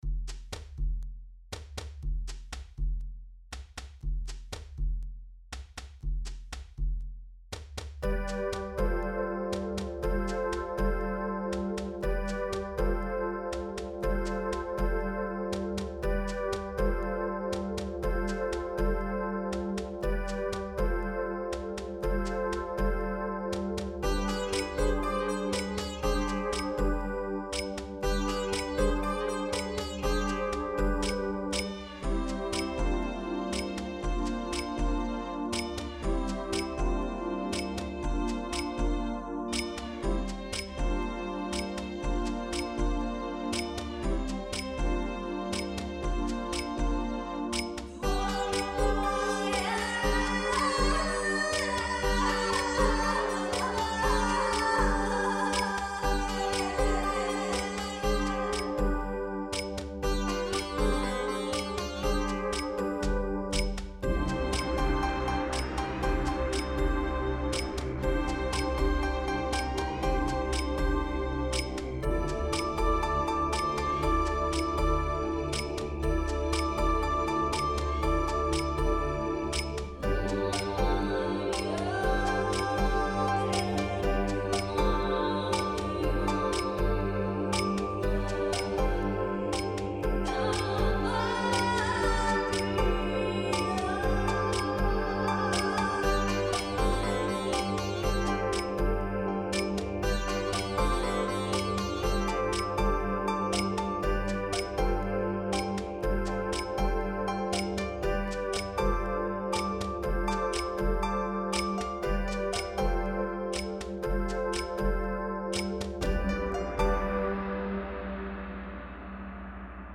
2’48 BPM: 120 Description: Exotic Mood: Joyful Instruments
Voice Genre: World music Composer/Artist
Loop Underscore
Reduced arrangement